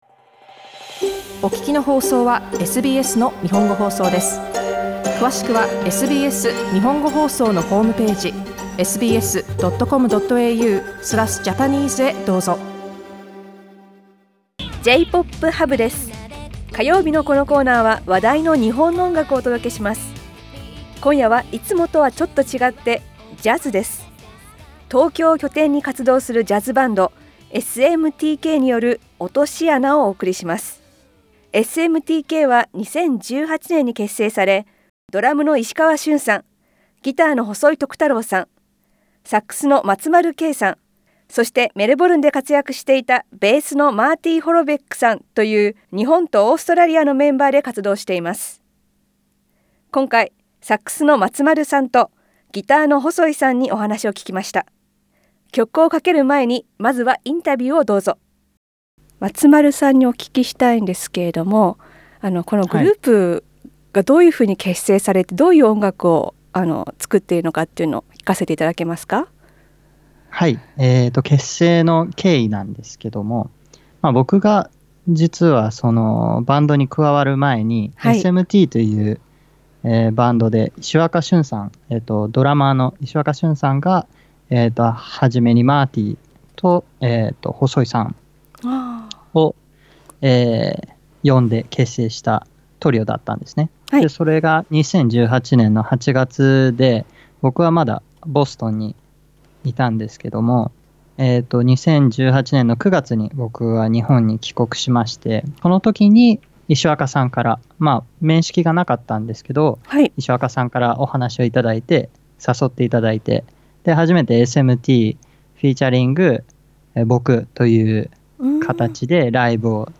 日本の話題の音楽を紹介する火曜日のコーナー「JPOP Hub」。今回はインタビュー付きの番外編です。